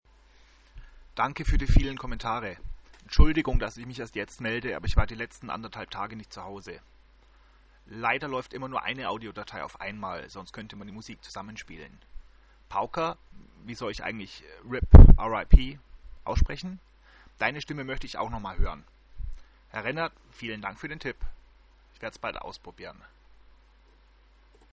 Die Zeilen habe ich dann noch mal für Sie als Kommentar gesprochen